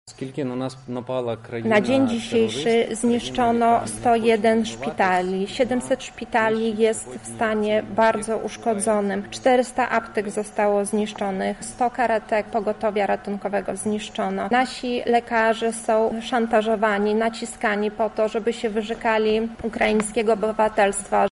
Zostaliśmy zaatakowani przez państwo terrorystyczne – mówi Minister Zdrowia Ukrainy Wiktor Liaszko: